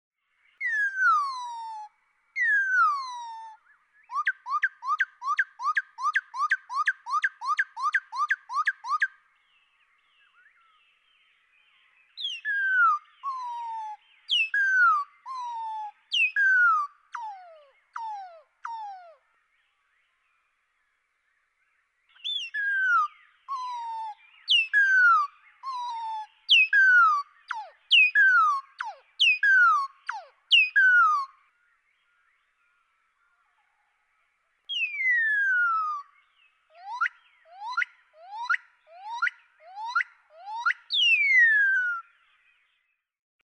Northern cardinal
One example of each of those four song types, slowed to half speed (A B C D).
123_Northern_Cardinal.mp3